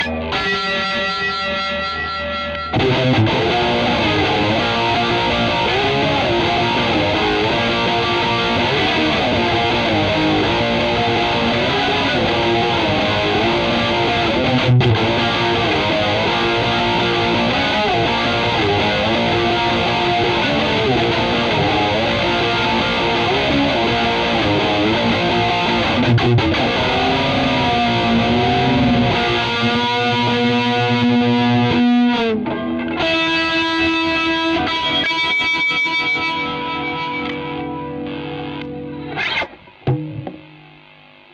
me playing a distorted, harsh punk riff i came up with that is heavily distorted and has whiny feedback. just like i love it. those chords and muted palm strums sound like they're gonna kill you, like